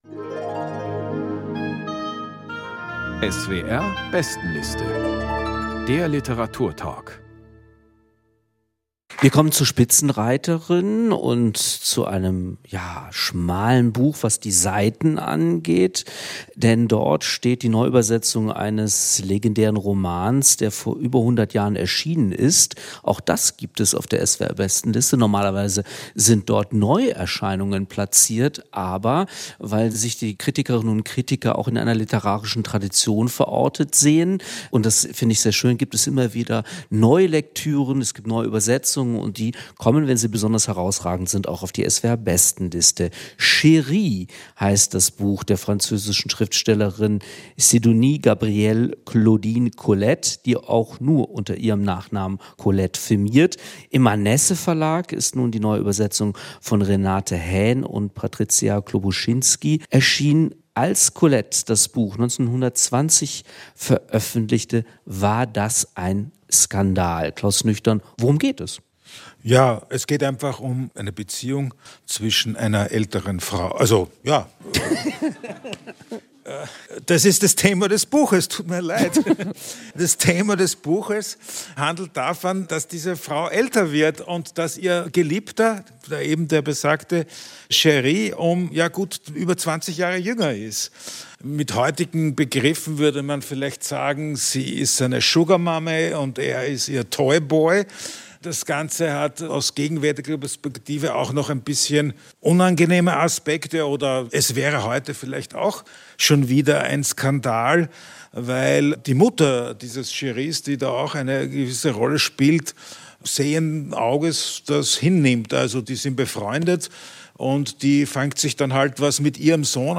Sidonie-Gabrielle Colette: Chéri | Lesung und Diskussion ~ SWR Kultur lesenswert - Literatur Podcast